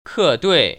[kèduì] 커뚜이  ▶